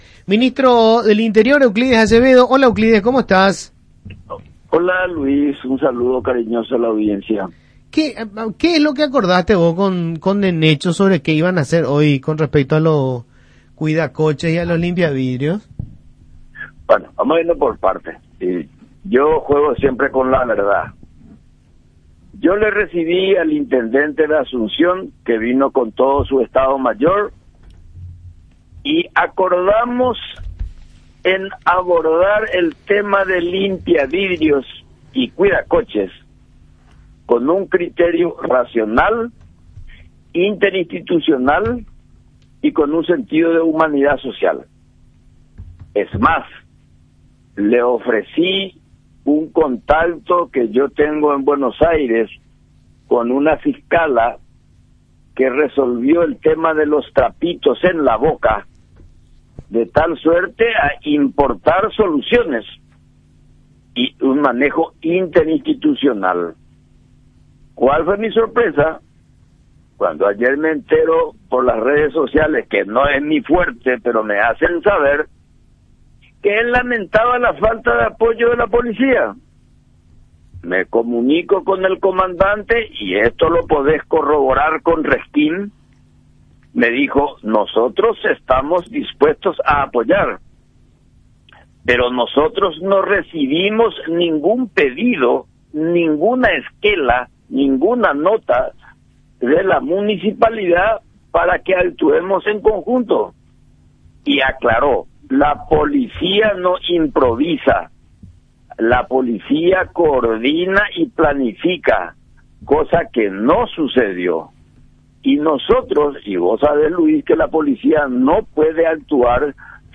El ministro del Interior, Euclides Acevedo, asegura que las autoridades de la Municipalidad de Asunción no dieron aviso formal del procedimiento para sacar a los cuidacoches y limpiavidrios de la vía pública.